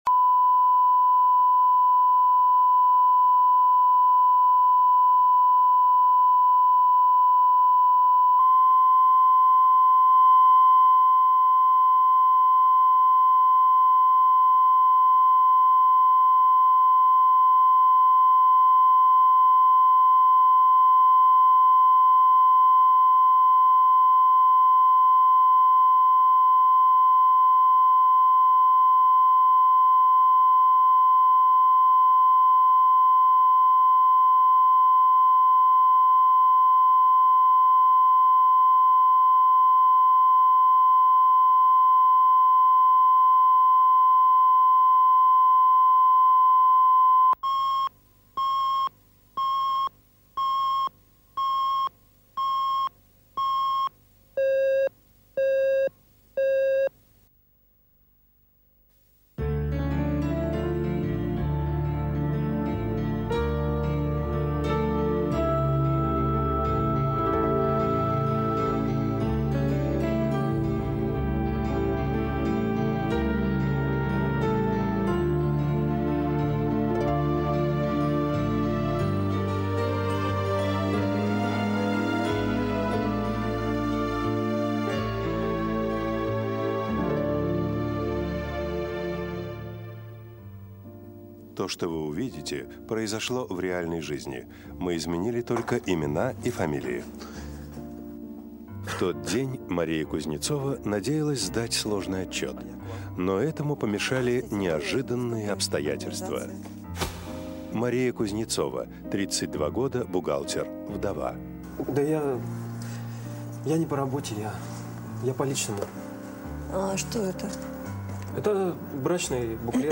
Аудиокнига Вдовье счастье | Библиотека аудиокниг